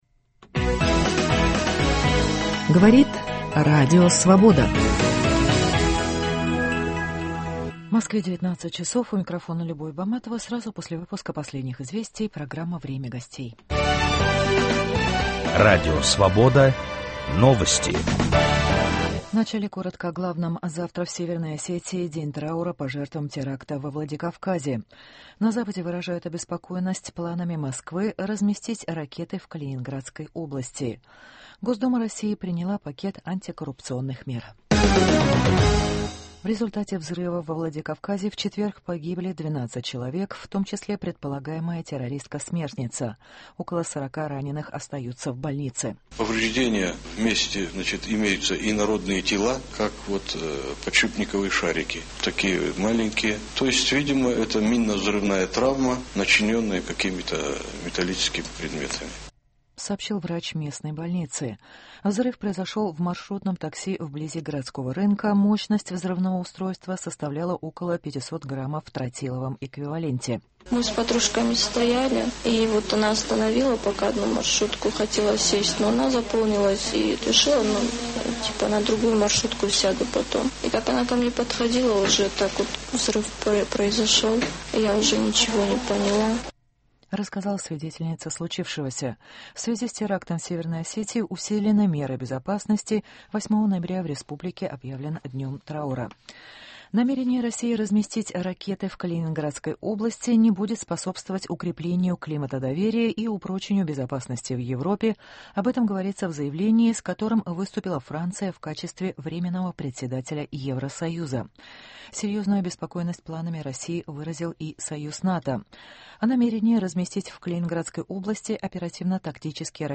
обсуждают: историк - профессор Юрий Афанасьев и писатель